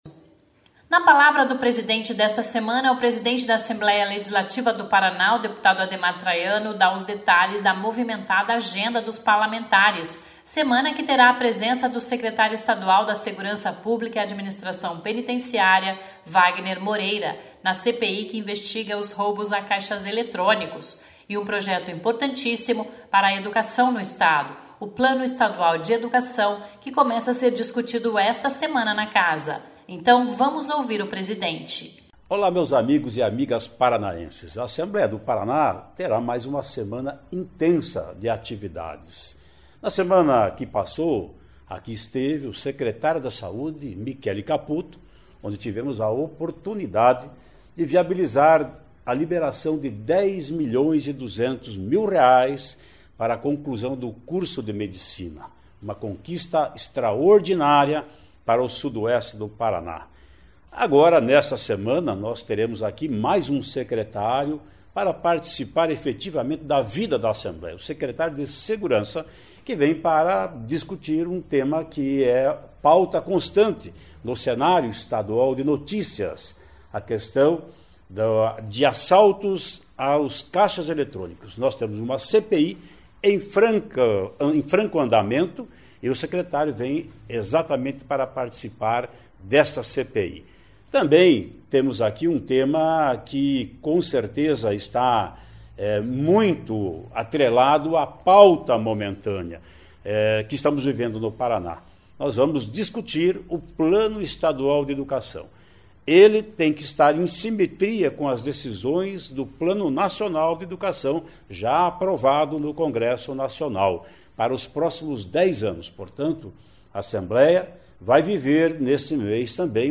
(Fala do presidente)